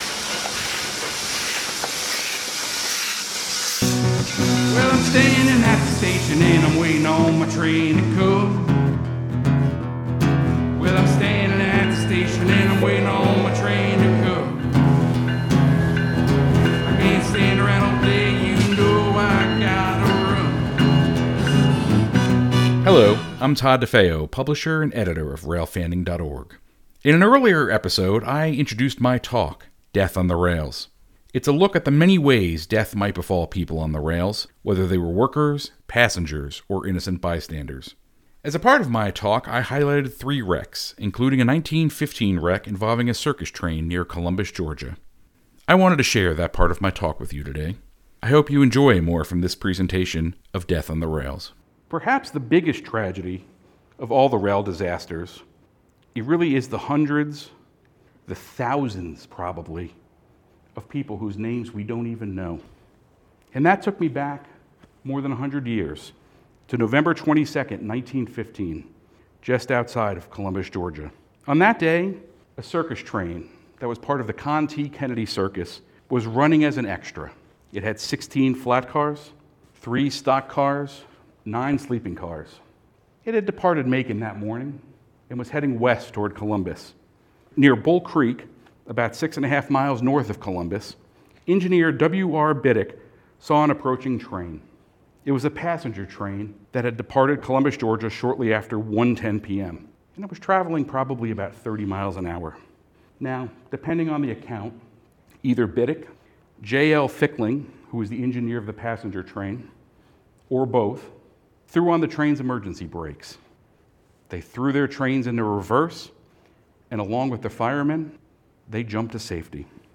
Steam Train: 1880s Train, recorded September 12, 2020, in Hill City, South Dakota. Show Notes This is an edited and condensed version of a presentation at the Smyrna Public Library, recorded on August 6, 2023.